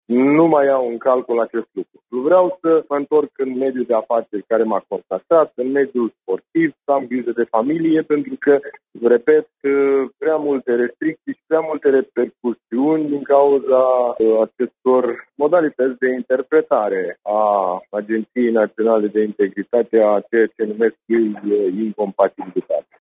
Deputatul PSD de Iași, Constantin Adăscăliței, a mai declarat pentru postul nostru de radio că se retrage din viața politică și nu va mai candida pentru nici o funcție la alegerile de anul viitor: